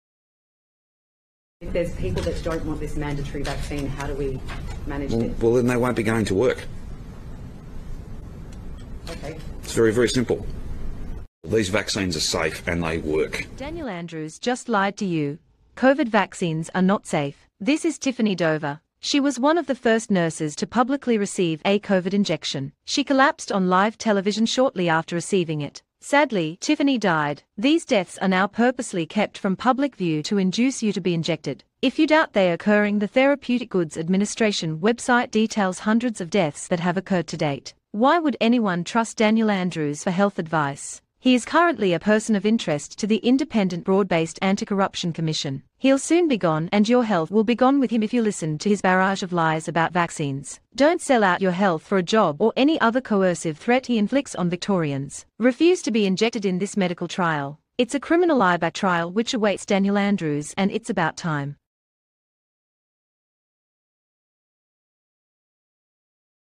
Australia Daniel Andrews and the live broadcast vaccination gone terribly wrong.